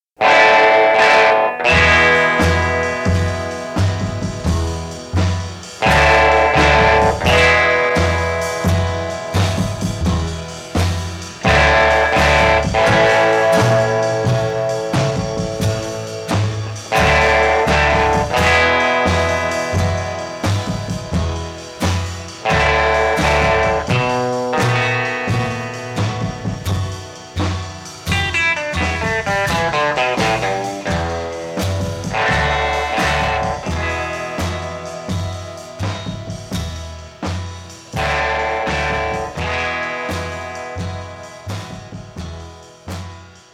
The power chord is stock-in-trade of rock guitarists.
That familiar jhang-jhang-jhang